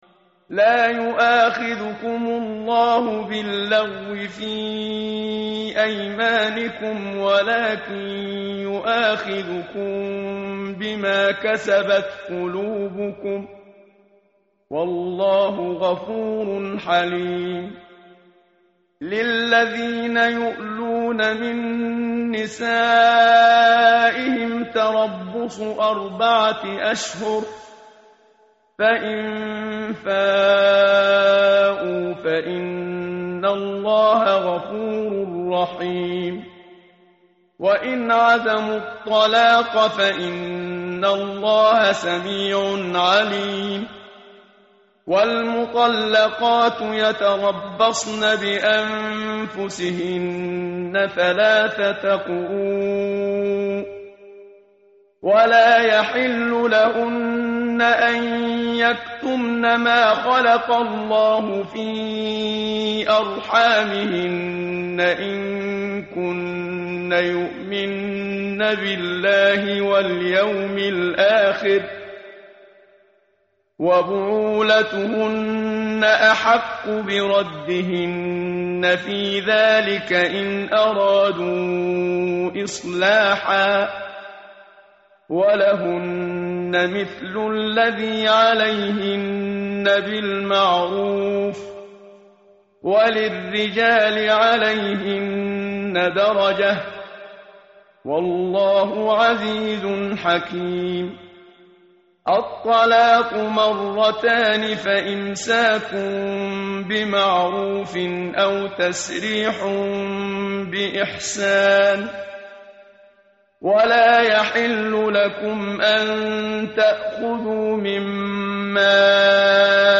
متن قرآن همراه باتلاوت قرآن و ترجمه
tartil_menshavi_page_036.mp3